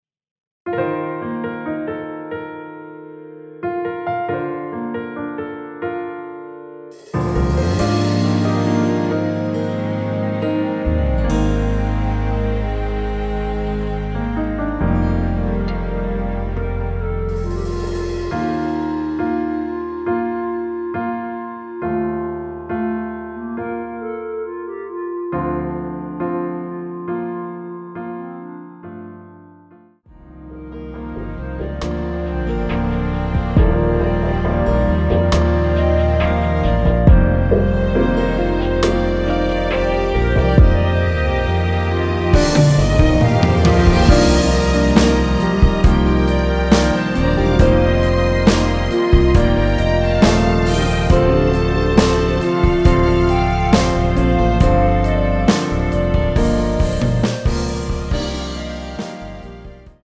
원키에서(-5)내린 멜로디 포함된 MR입니다.(미리듣기 확인)
Bb
앞부분30초, 뒷부분30초씩 편집해서 올려 드리고 있습니다.